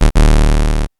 beep buzz buzzer bzzzt computer digital electronic error sound effect free sound royalty free Sound Effects